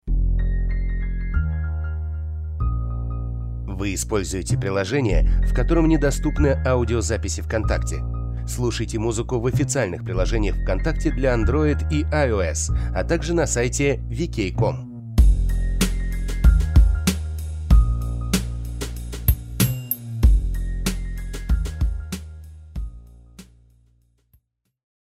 демо голоса Категория: Аудио/видео монтаж
Различные голосовые подачи.